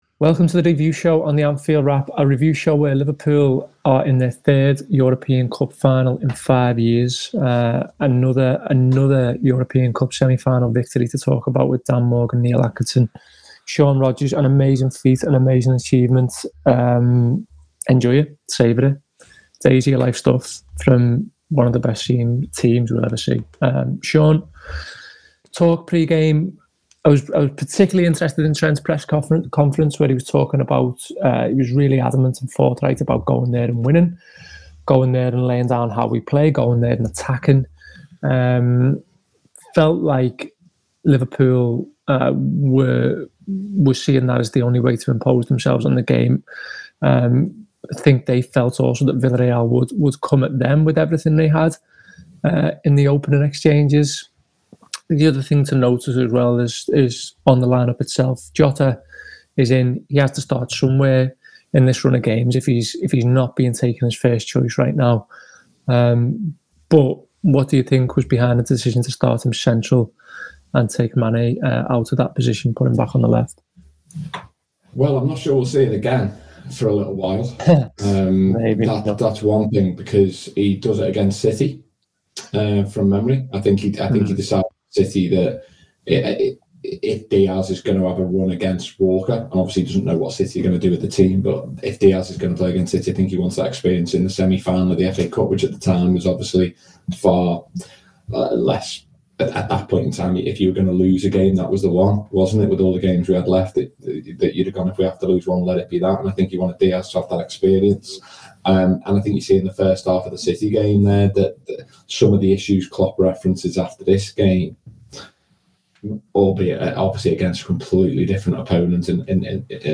Below is a clip from the show – subscribe for more Villarreal v Liverpool review chat…